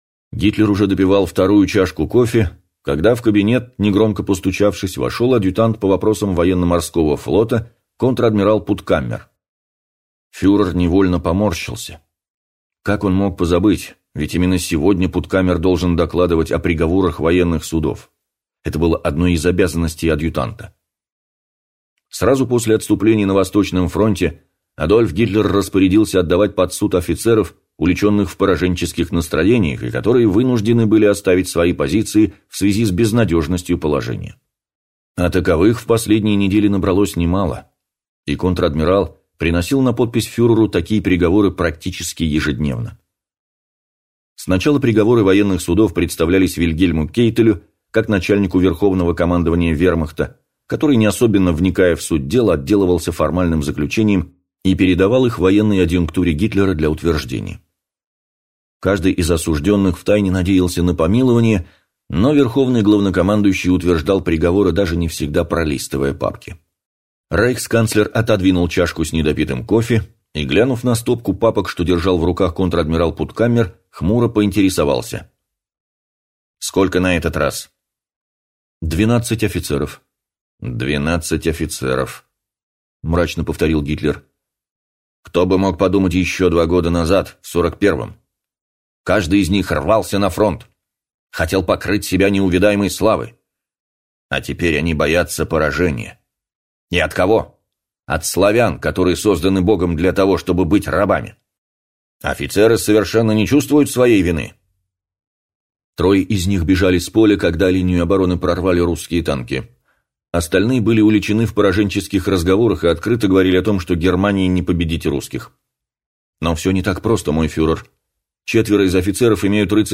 Аудиокнига След предателя | Библиотека аудиокниг